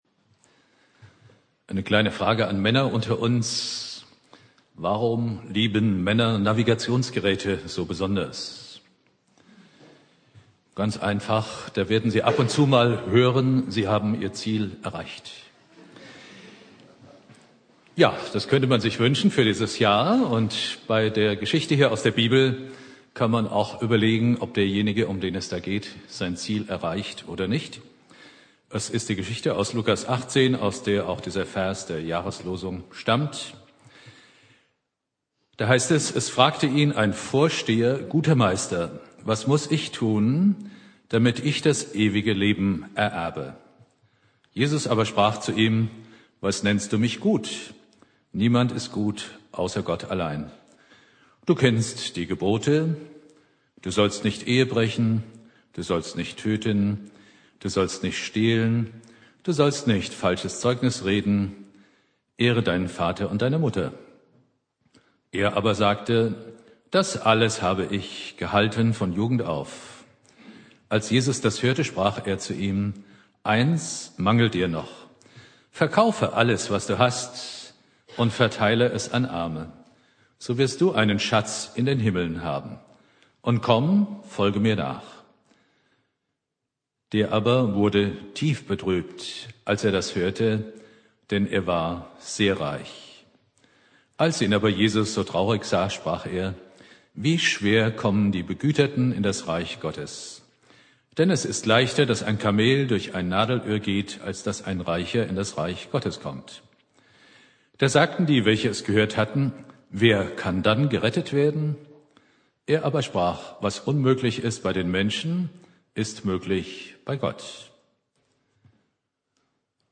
Predigt
Neujahr